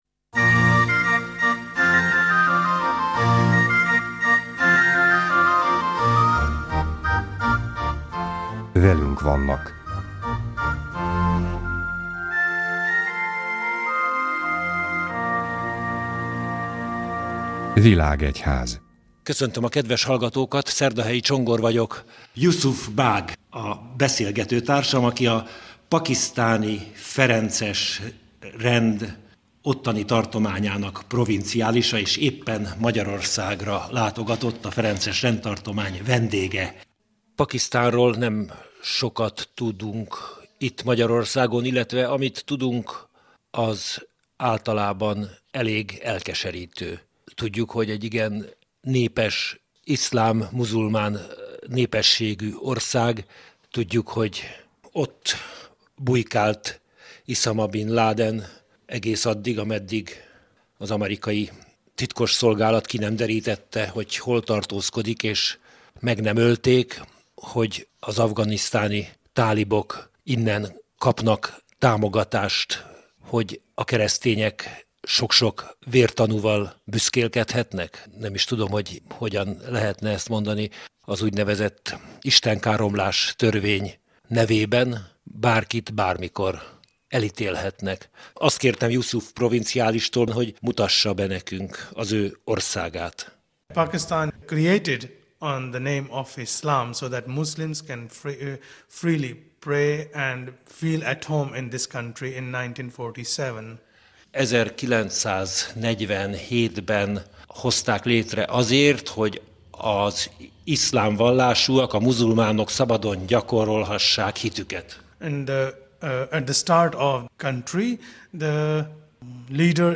Ez utóbbi, amely június 7-én hangzott el a Világ-egyház című műsorban, itt meghallgatható.